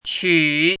怎么读
[ qǔ ]
qu3.mp3